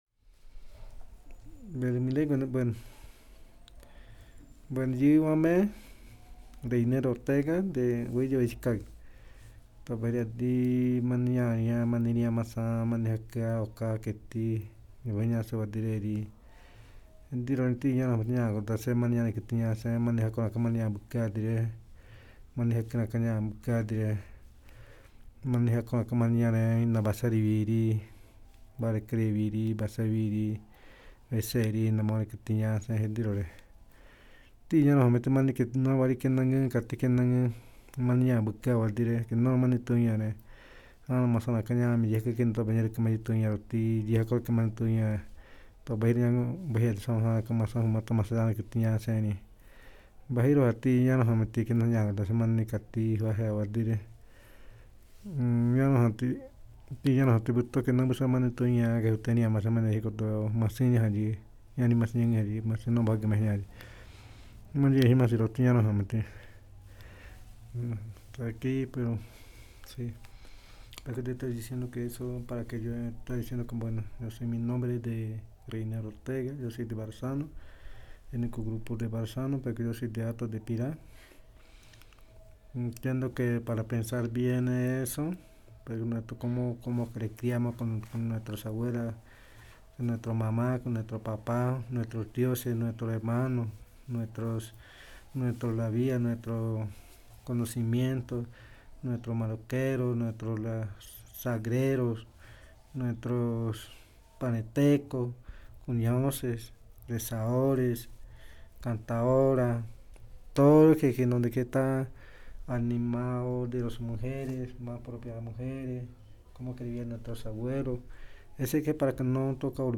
Conversación grabada por 4direcciones en Bogotá 2021.